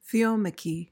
PRONUNCIATION:
(thee-OM-uh-kee)